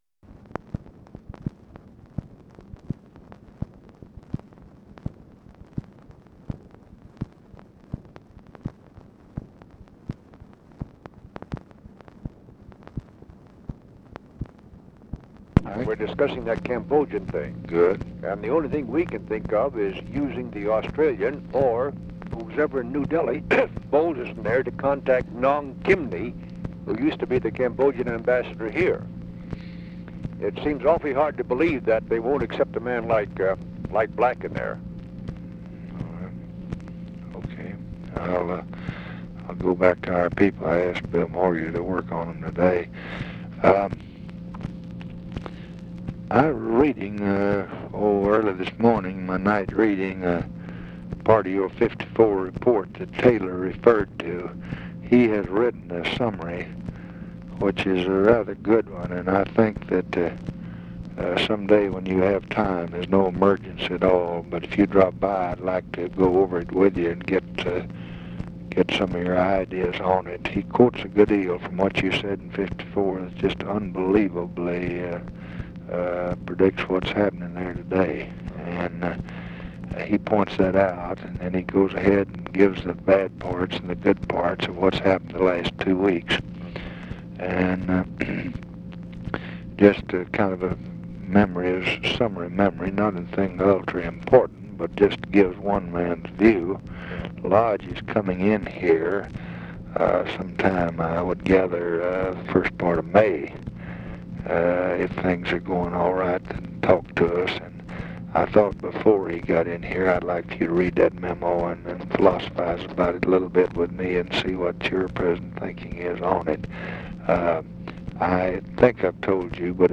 Conversation with MIKE MANSFIELD, April 21, 1966
Secret White House Tapes